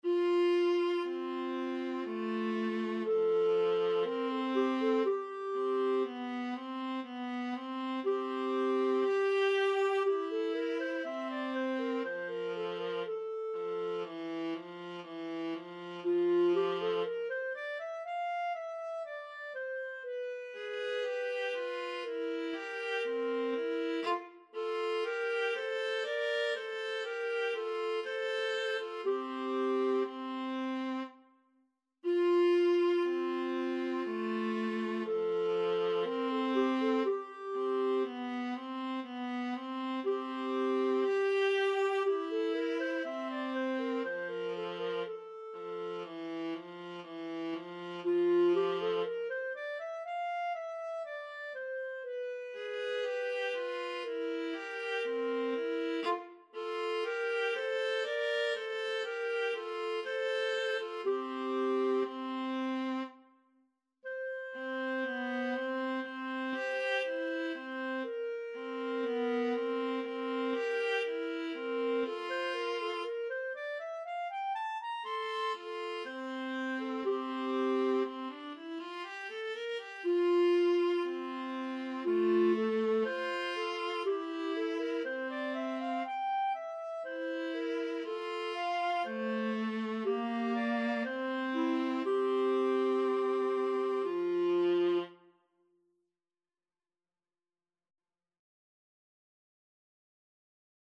4/4 (View more 4/4 Music)
Classical (View more Classical Clarinet-Viola Duet Music)